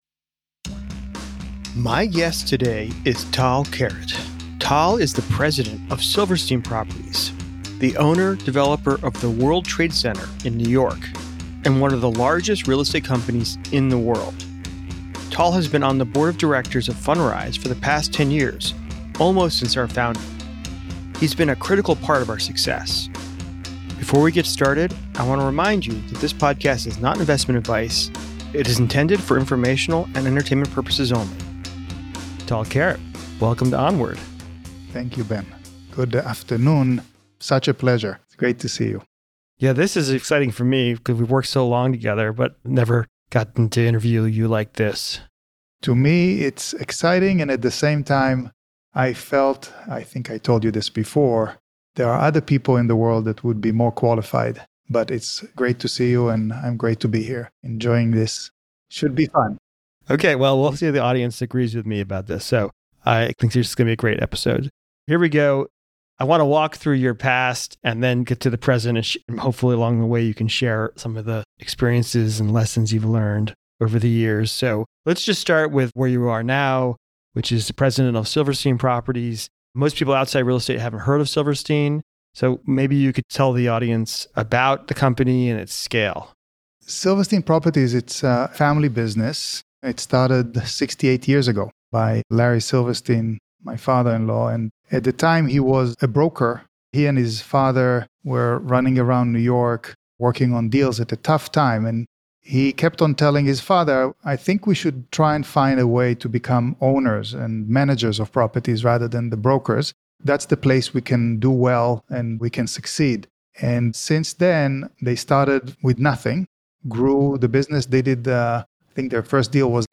This conversation offers valuable insights into building enduring projects and resilient teams.